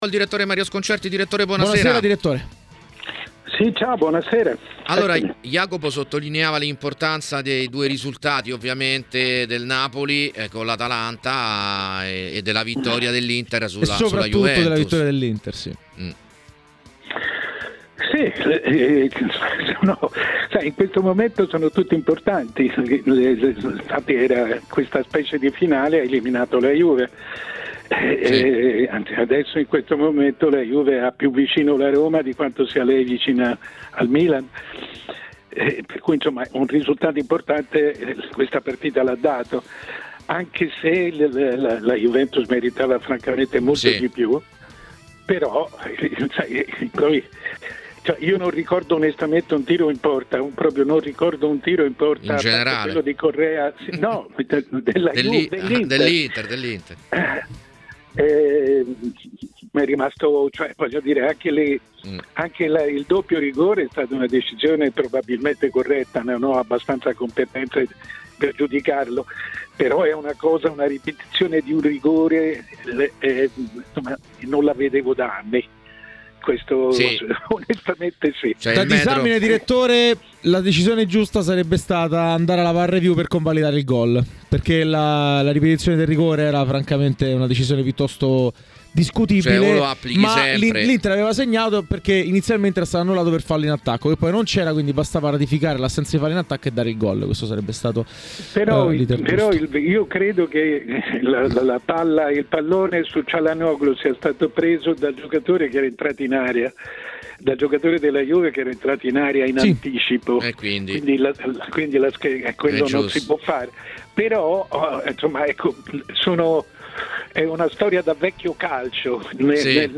Intervenuto ai microfoni di TMW Radio, il direttore Mario Sconcerti analizza così i temi della 30esima giornata di Serie A: “L’Inter è l’unica tra le prime che non ha vinto, a questo punto è una distanza che comincia a pesare.